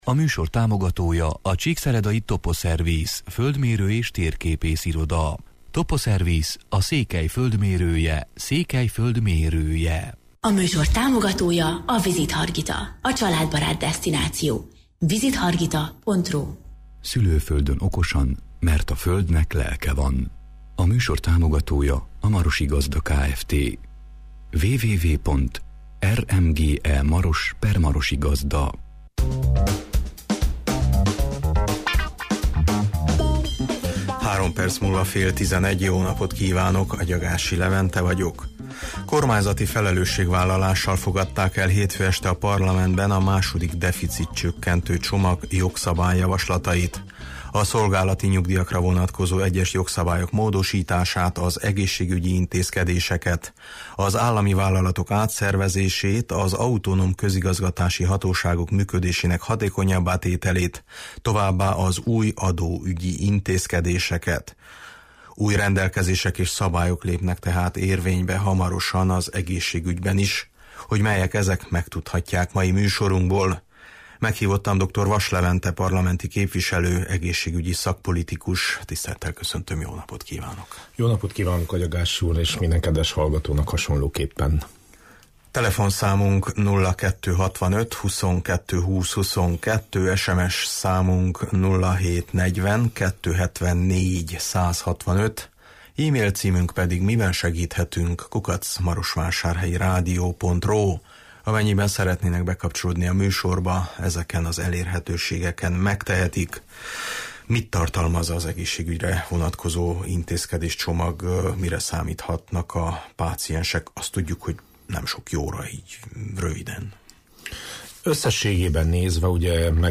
Meghívottam dr. Vass Levente parlamenti képviselő, egészségügyi szakpolitikus